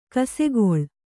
♪ kasegoḷ